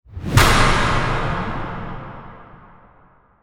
MegaSlap.wav